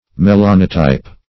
Melainotype \Me*lai"no*type\, n.